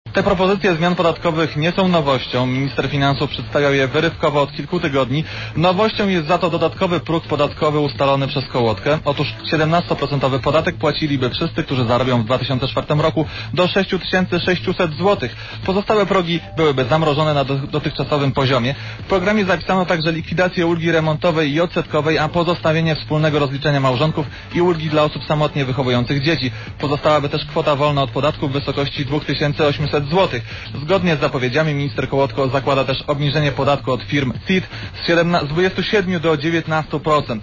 Relacja reportera Radia Zet (150Kb)